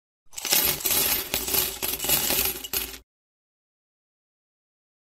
moedas.mp3